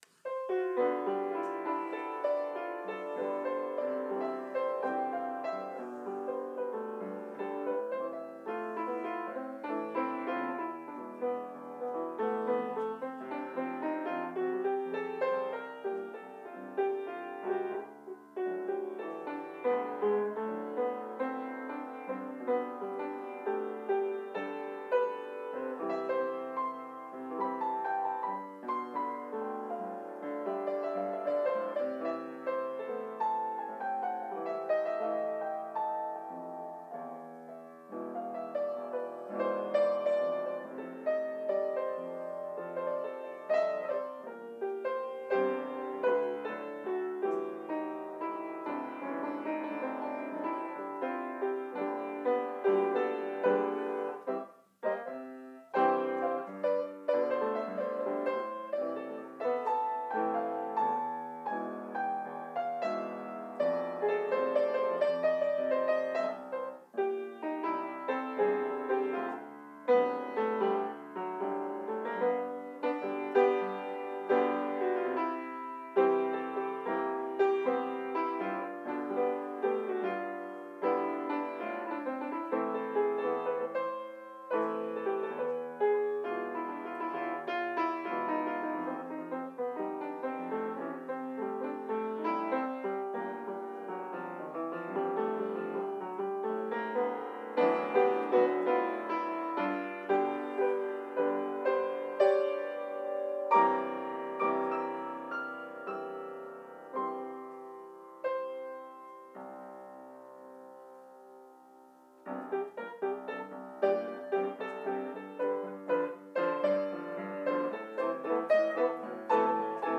J’ai des dizaines d’enregistrements de mes improvisations, qui durent de 1 à 3 minutes en général, et le choix déposé ici est très problématique, car j’ai la conscience aiguë de ne pas avoir les moyens subjectifs d’opérer une « bonne » sélection.
Je pratique le ‘pianon’ plus que le piano.
…En ignorant, sans partitions, improvisant sur un éventail plus que limité d’accords, de rythmes et de tonalités.